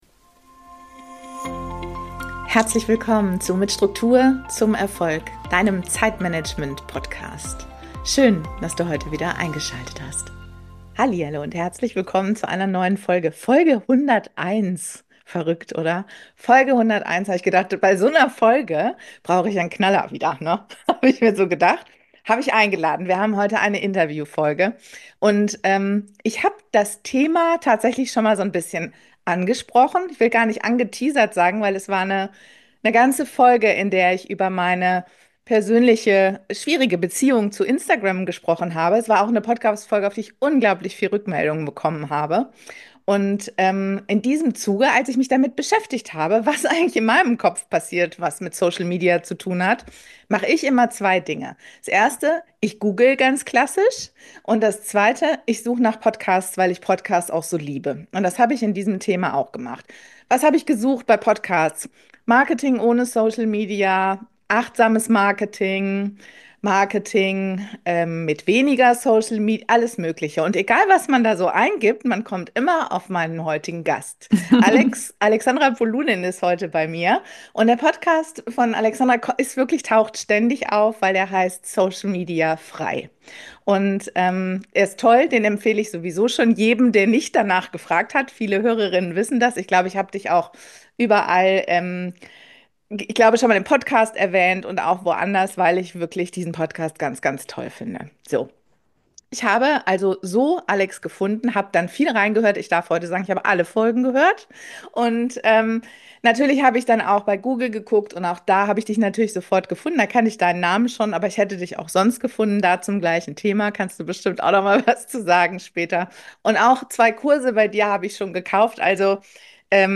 Interview ~ Mit Struktur zum Erfolg - Zeitmanagement. Planung. Umsetzung Podcast